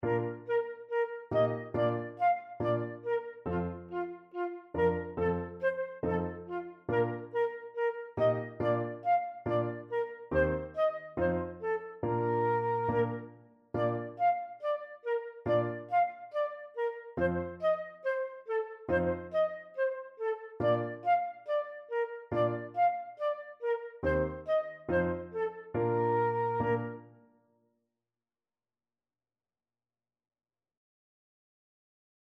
Fast = c. 140
Flute  (View more Beginners Flute Music)